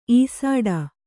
♪ īsāḍa